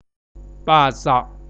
Labial
Pa-zauk {pa.sauk} <))